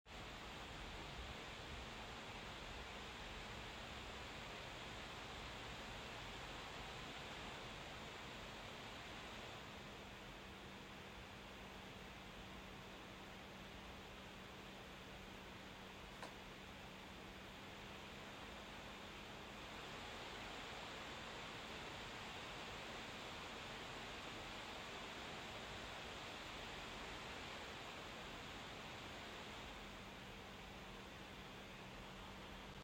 Den Unterschied macht, wie langsam dieser Wechsel vonstatten geht: Im höchsten Profil wechselt das GeekBook quasi ununterbrochen zwischen „laut“ und „leise“, im kleinsten verharrt es länger an den beiden Extremen.
Geekom GeekBook X14 Pro: Kühlsystem im Modus Energiesparen (CB 2026 MC)